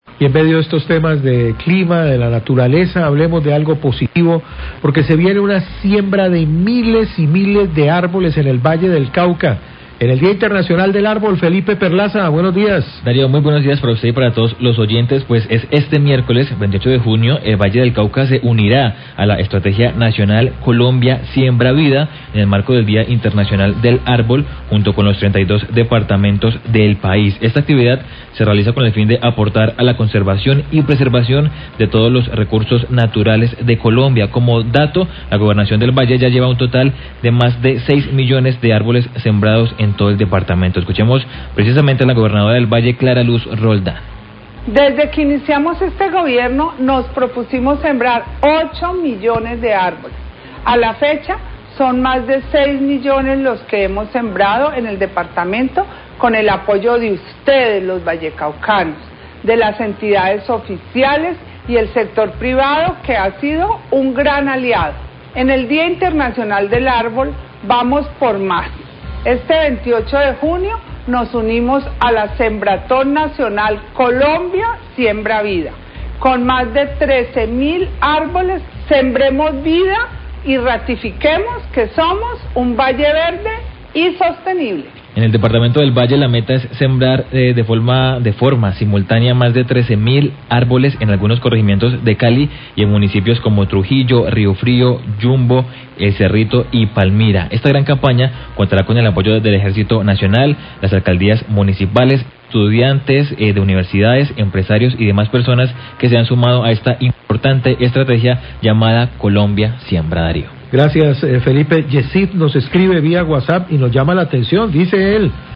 Gobernadora del Valle habla de sembratón nacional en el marco del Día Internacional del Arbol
Radio